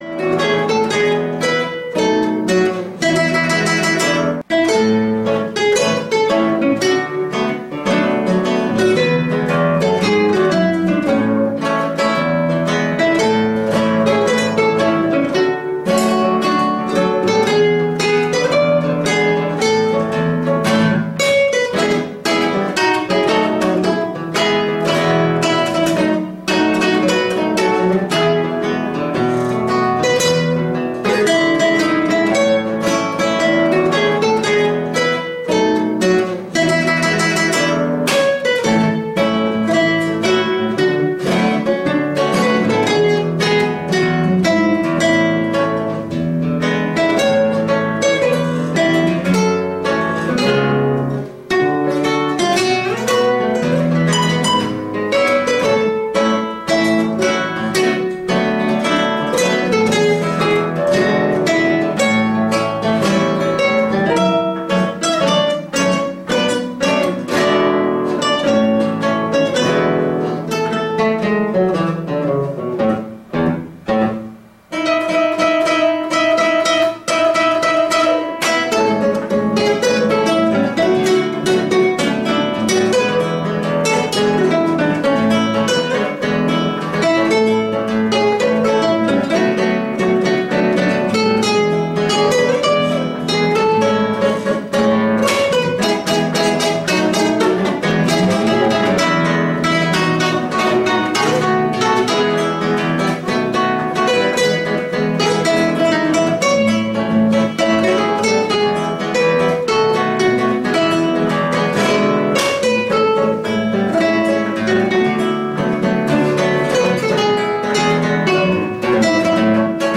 От китайских товарисчей..., бодренько и душевно.....
Наверно, возьму фонограмму на фортепиано.